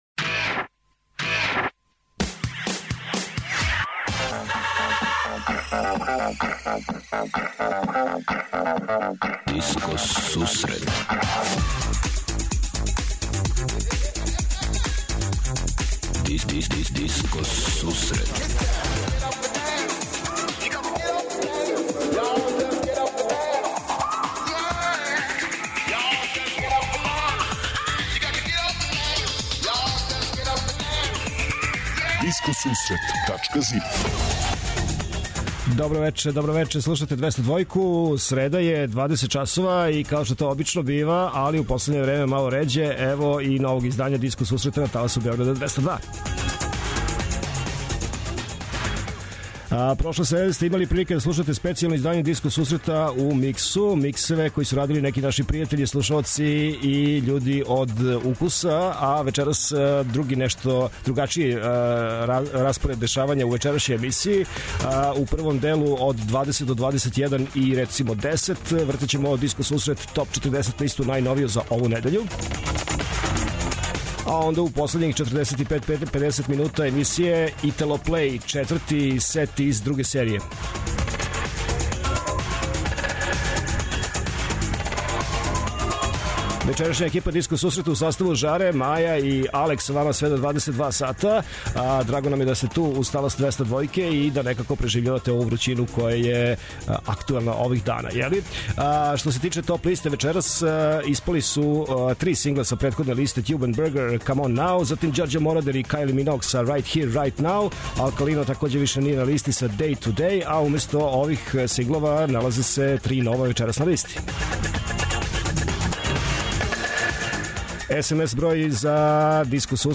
Диско сусрет је емисија посвећена најновијој и оригиналној диско музици у широком смислу, укључујући све стилске утицаје других музичких праваца - фанк, соул, РнБ, итало-диско, денс, поп. Непосредан контакт са слушаоцима уз пуно позитивне енергије је основа на којој ова емисија гради забаву сваке среде.